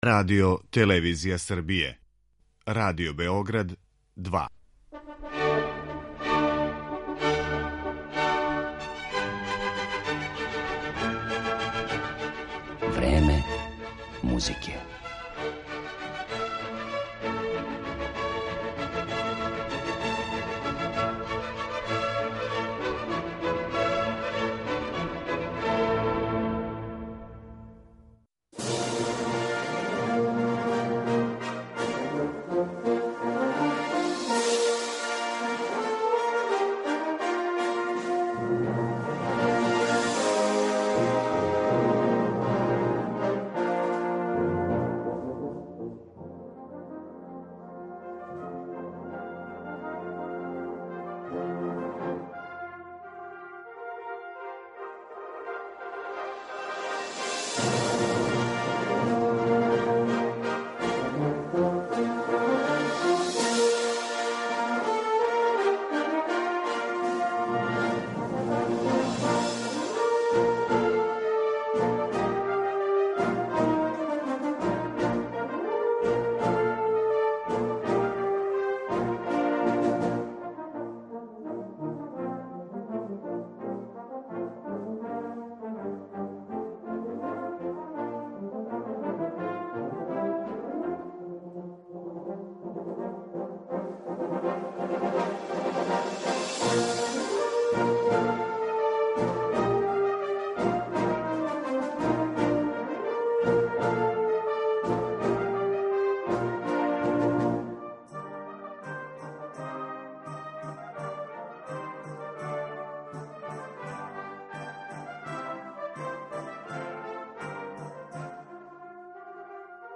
моћи ћете да слушате одломке неких од најзанимљивијих награђених издања.